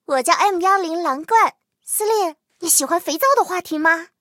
M10狼獾登场语音.OGG